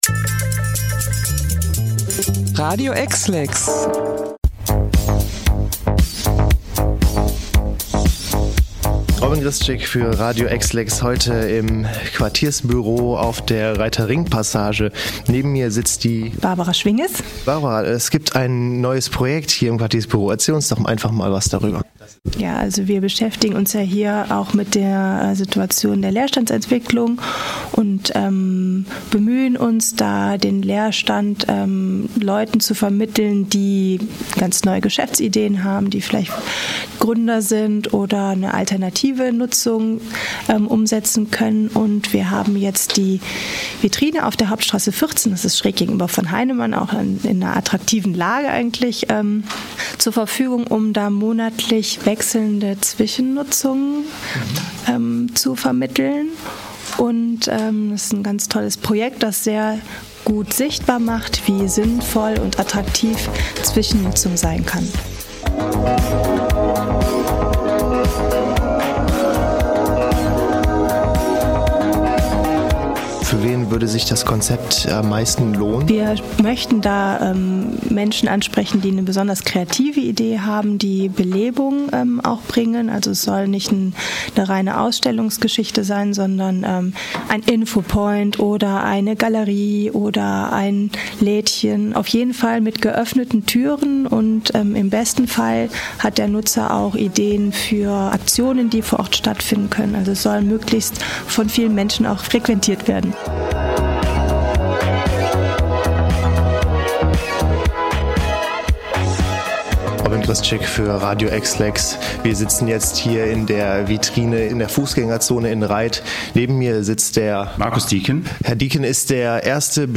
Interview-50-Kubik-RG-TB.mp3